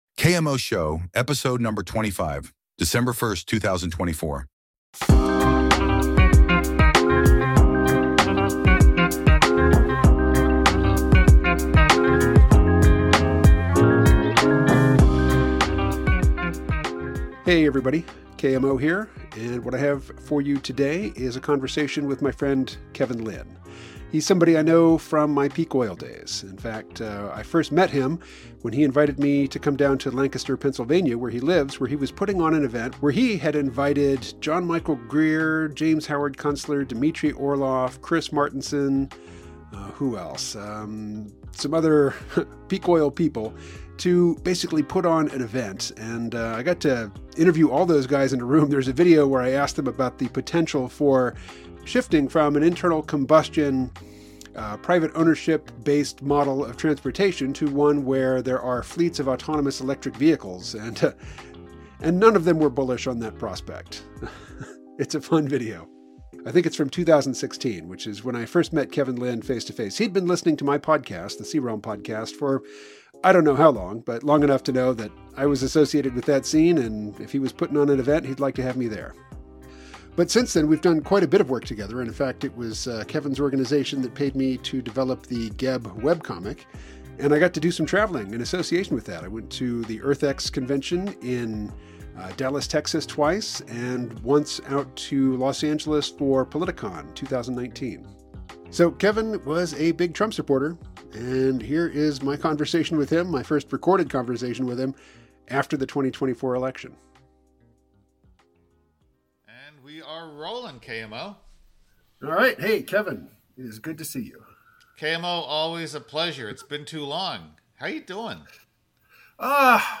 The conversation begins with an AI-generated introduction acknowledging their shared history in the Peak Oil community and mutual interest in Rudyard Lynch's analysis of historical crisis patterns.